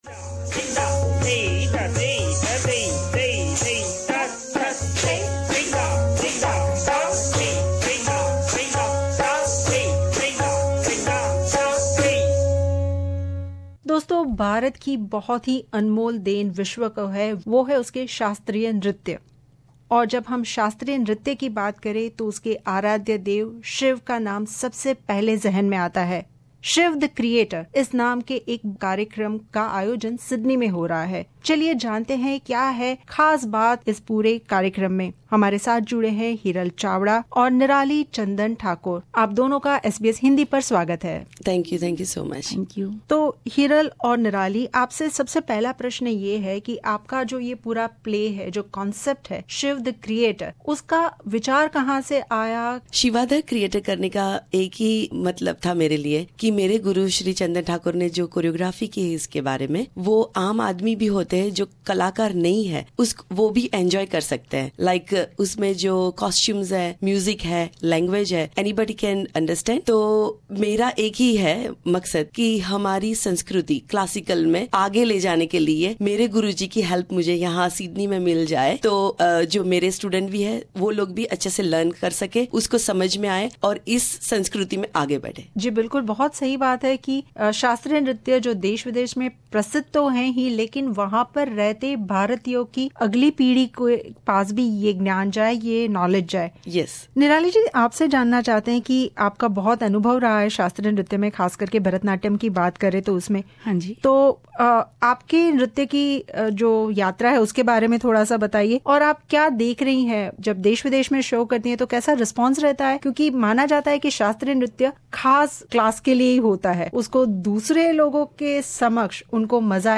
मुलाकात